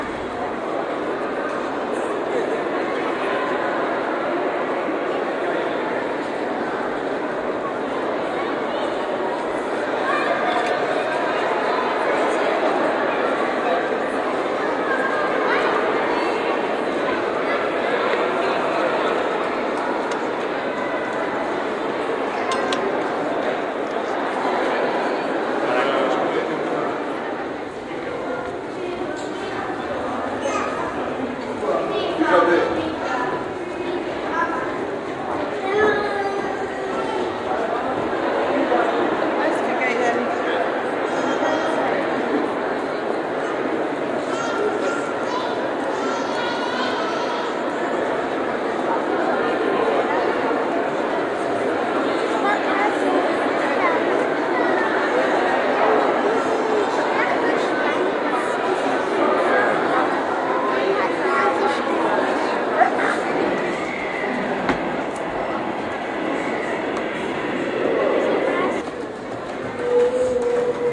描述：伦敦，博物馆内。大厅，无法辨别的声音，脚步声，很多人，高氛围的噪音。
Tag: 氛围 伦敦 声音 博物馆 现场录音